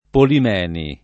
vai all'elenco alfabetico delle voci ingrandisci il carattere 100% rimpicciolisci il carattere stampa invia tramite posta elettronica codividi su Facebook Polimeni [ polim $ ni ] cogn. — qualche famiglia, però, pol & meni